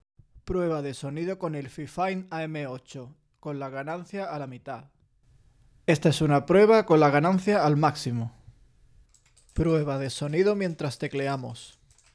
Prueba de sonido
Os hemos dejado una captura del Fifine AmpliGame AM8, donde, en primer lugar probamos el micrófono con la ganancia a la mitad.
Si el ruido del entorno está más controlado, podremos fijar la ganancia al máximo, y aún es capaz de darnos una voz clara sin artefactos ni ruidos.
En el tercer segmento con la ganancia al 75% aproximadamente, la captura se mantiene sumamente limpia teniendo en cuenta que justo detrás tenemos un teclado sumamente ruidoso como es el Hunstman Elite de Razer con switches Purple.
Notamos cómo se transmite el ruido procedente de la torre del ordenador, incluso con el micrófono apoyado en la alfombrilla.